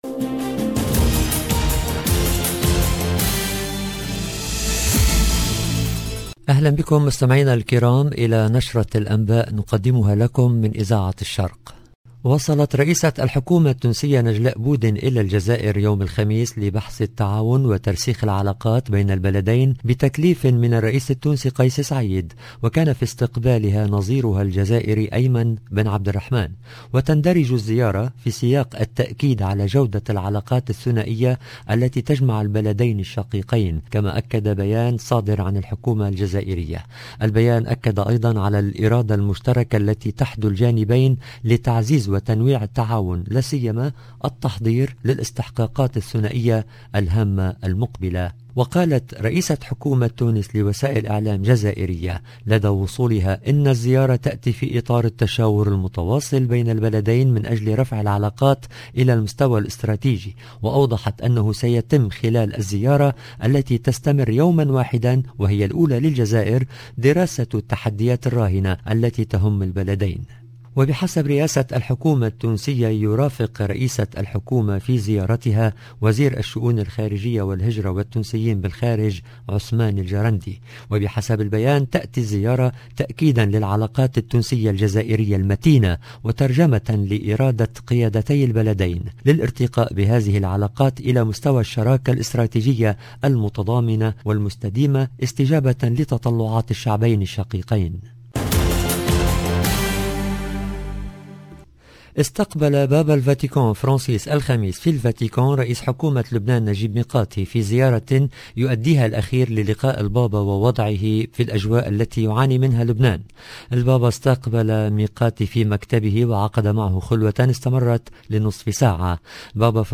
LE JOURNAL DU SOIR EN LANGUE ARABE DU 25/11/21